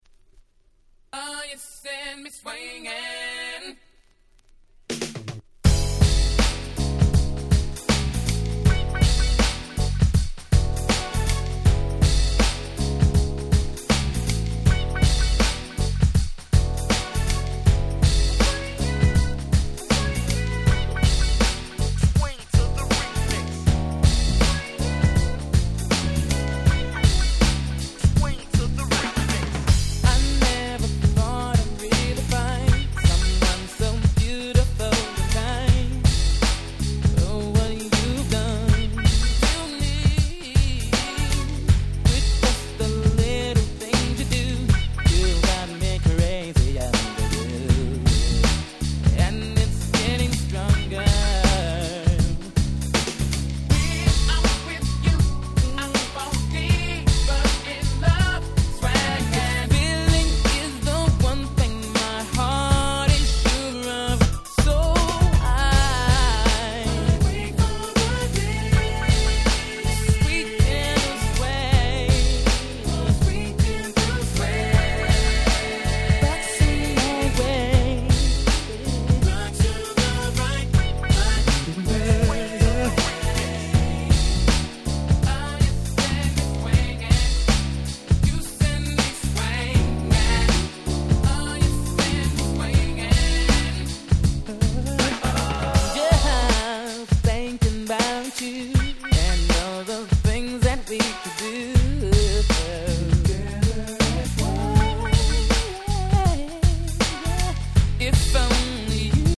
93' Nice Mid Dancer !!
ハネたBeatが気持ち良い極上Mid Dancerです！